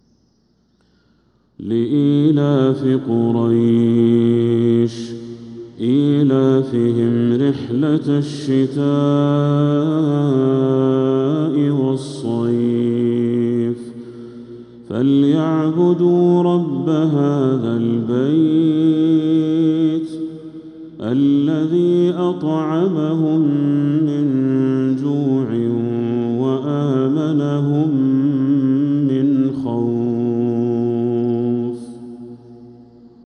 سورة قريش كاملة | شوال 1446هـ > السور المكتملة للشيخ بدر التركي من الحرم المكي 🕋 > السور المكتملة 🕋 > المزيد - تلاوات الحرمين